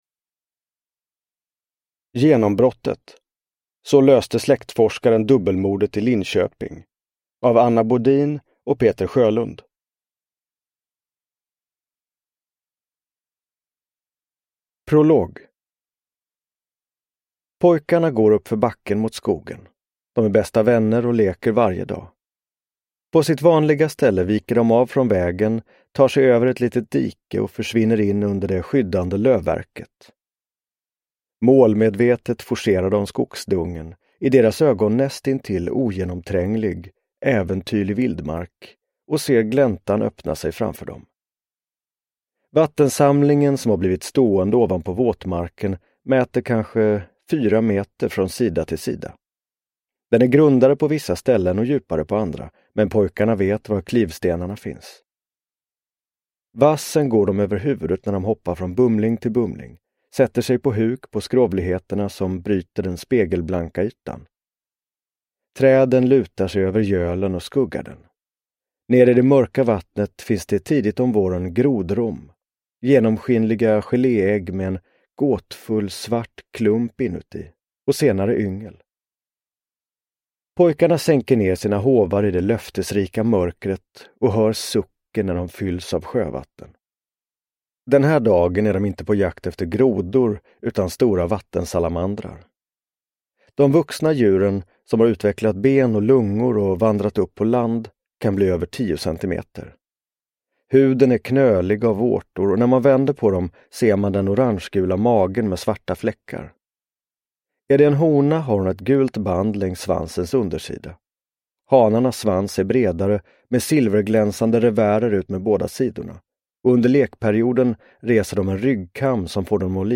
Genombrottet : så löste släktforskaren dubbelmordet i Linköping – Ljudbok – Laddas ner
Uppläsare: Martin Wallström